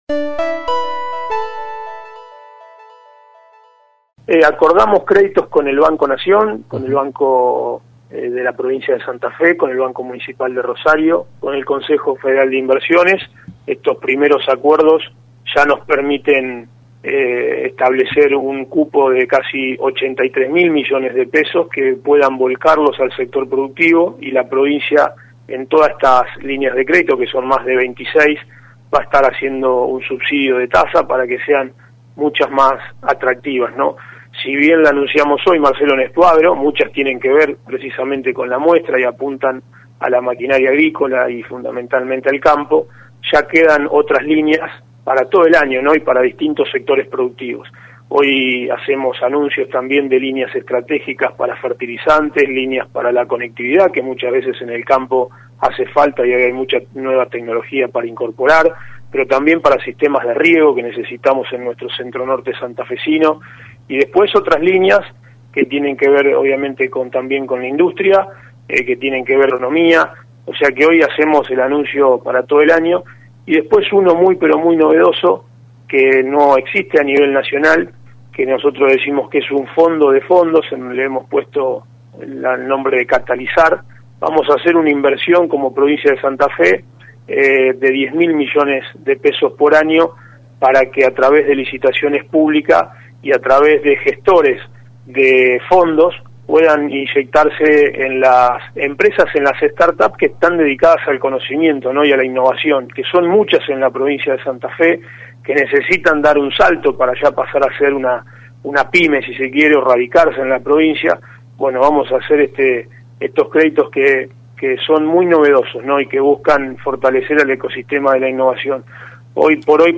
El ministro de Desarrollo Productivo de la provincia de Santa Fe, Gustavo Puccini en contacto con LT3 anticipó los anuncios que el gobierno provincial realizará en el marco de la Expoagro que se desarrollará en la localidad de San Nicolás del 11 al 14 de marzo.